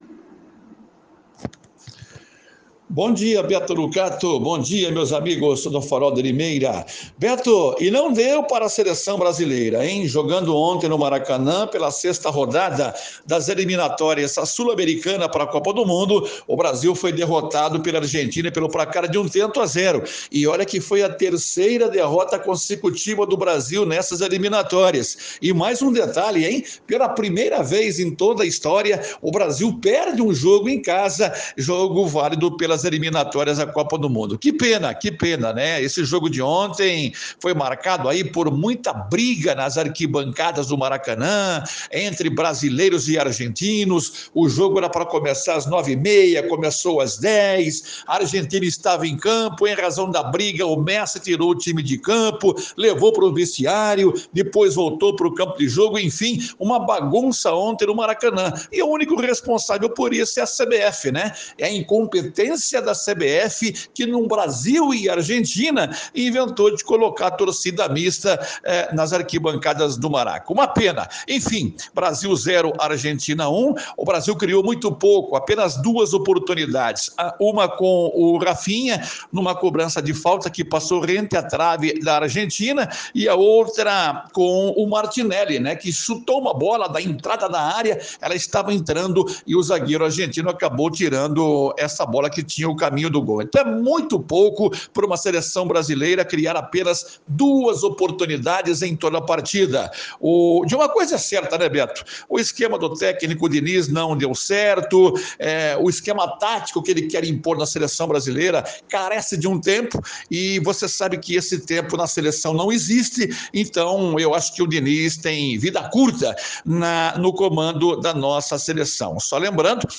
boletim esportivo